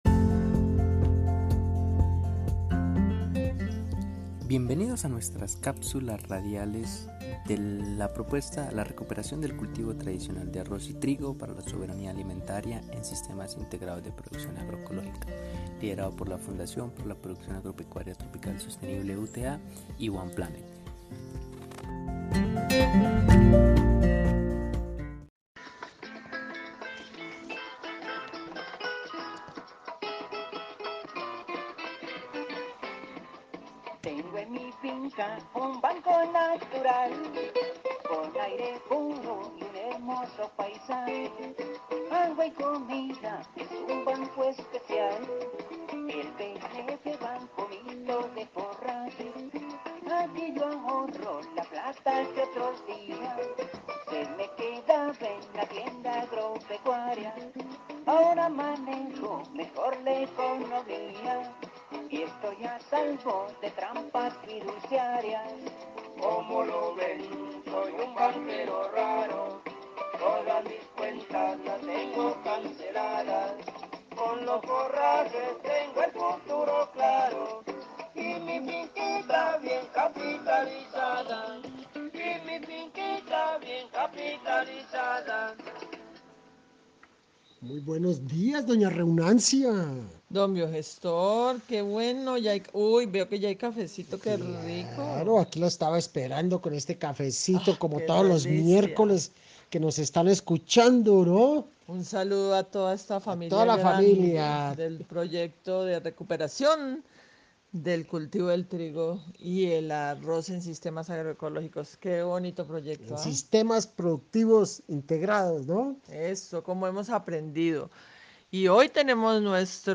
A todos los participantes del proyecto de recuperación del trigo y el arroz UTA-One Planet quienes por medio de sus teléfonos celulares desde las montañas de la ruralidad Colombiana han participado de estas jornadas de conversación y construcción colectiva de saberes y conocimientos